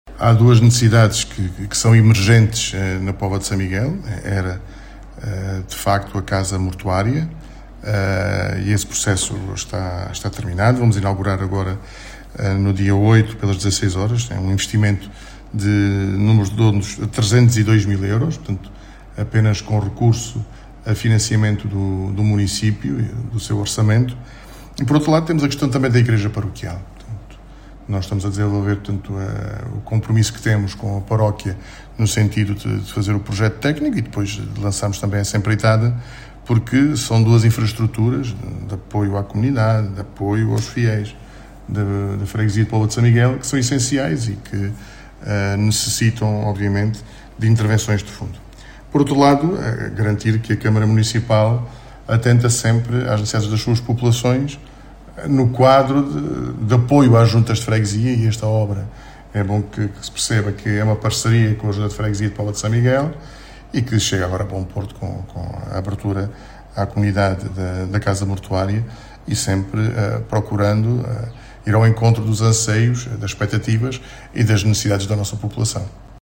As explicações são do presidente da Câmara Municipal de Moura Álvaro Azedo.